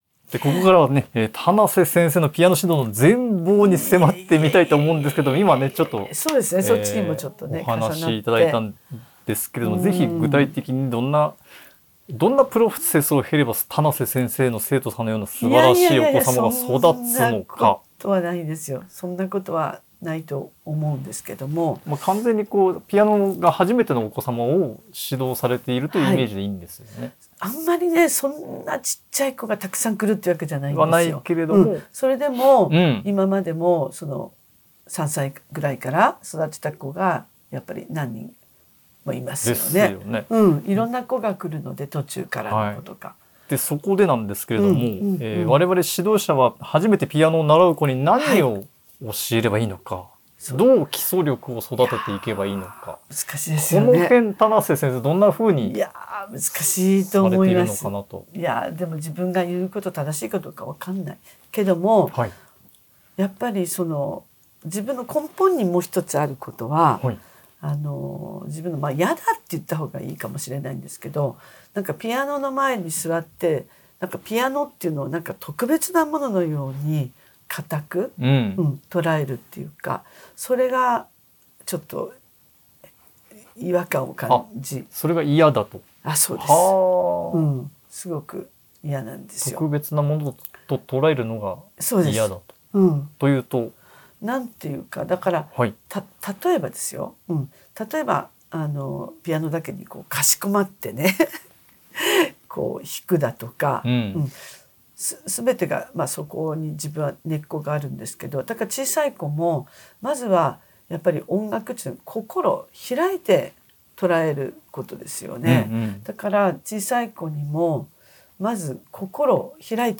音声講座が体験できるサンプル音声